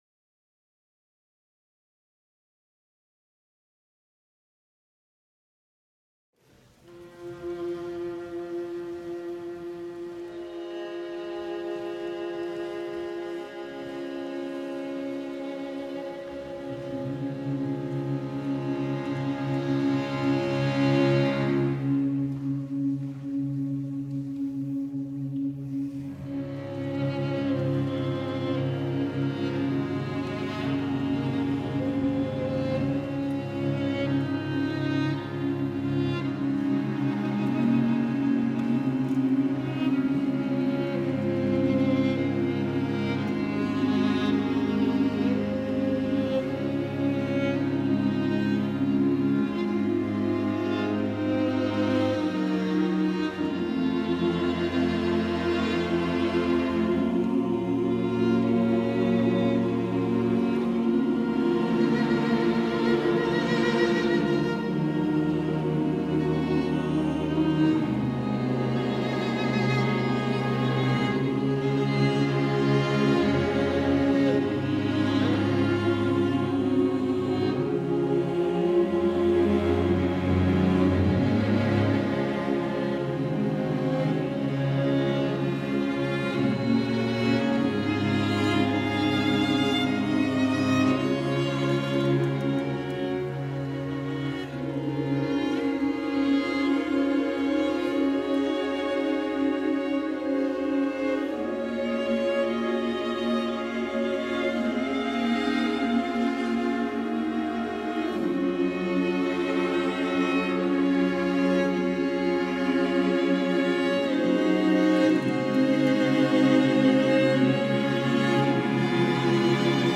Live Performance Recording